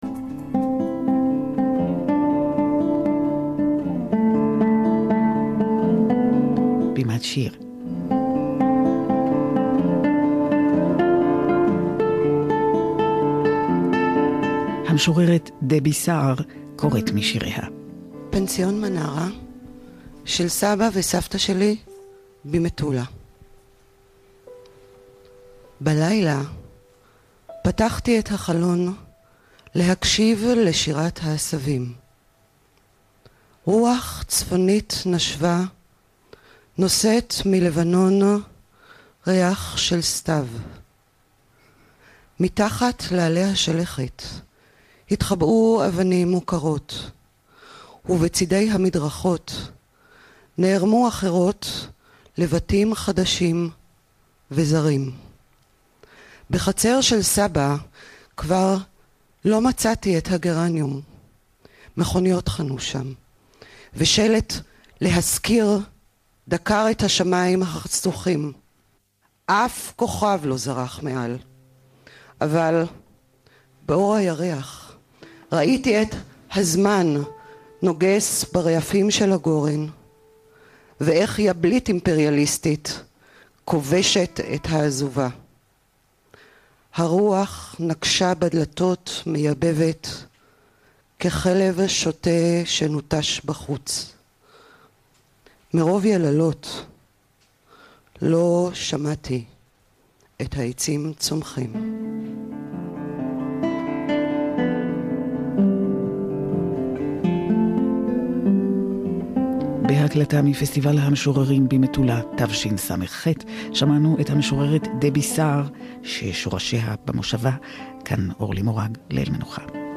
השיר פנסיון מנרה הוקלט בפסטיבל המשוררים במטולה ושודר בתכנית 'בימת שיר' ברשת ב'